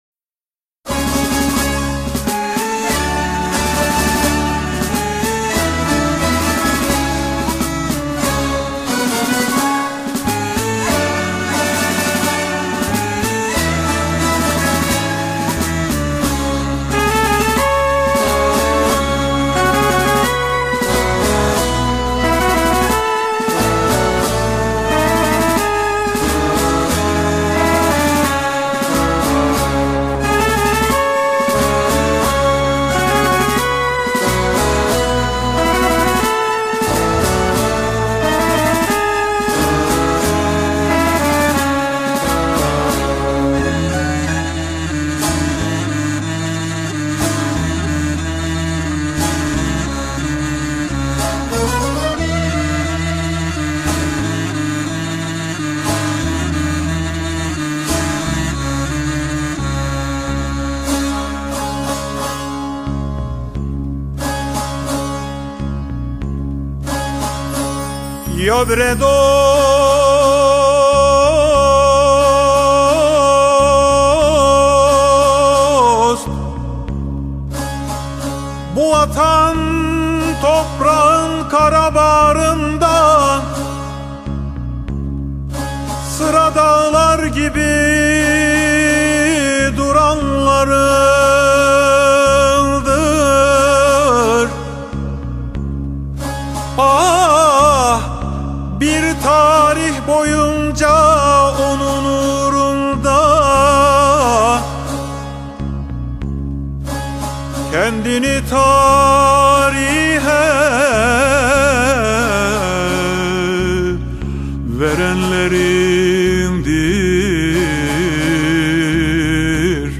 Etiketler: urfa, türkü